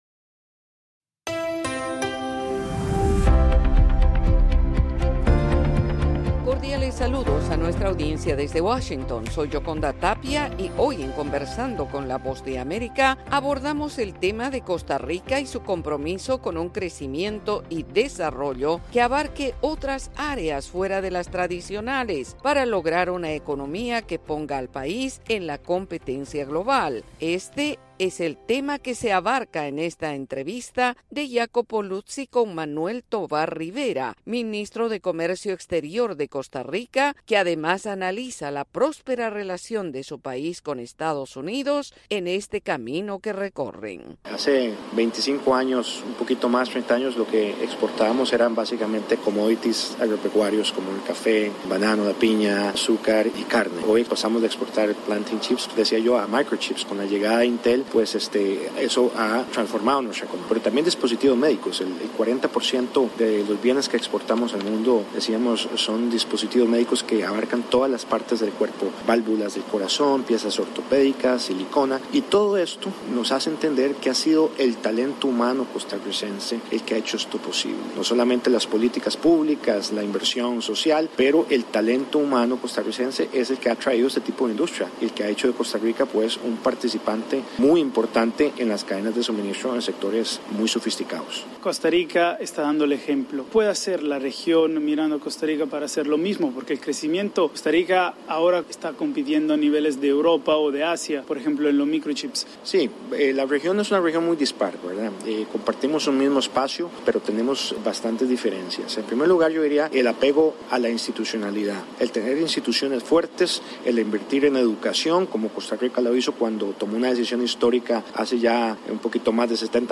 Conversamos con Manuel Tovar Rivera, ministro de Comercio Exterior de Costa Rica, destacando los esfuerzos de producción de diverso tipo con miras a la exportación diversa para fortalecer su economía.